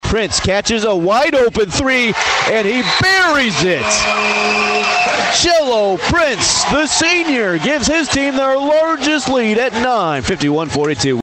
Nowata hosted rival Dewey in a pair of basketball contests, with coverage of the boys game on KRIG 104.9.
nowata shot vs Dewey.mp3